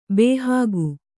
♪ bēhāgu